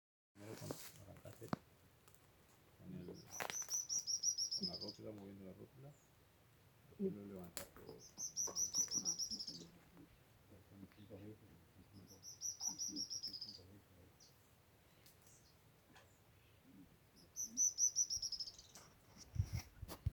Arredio (Cranioleuca pyrrhophia)
Nome em Inglês: Stripe-crowned Spinetail
Detalhada localização: Reserva de Usos Múltiples "el Guayabo"
Condição: Selvagem
Certeza: Observado, Gravado Vocal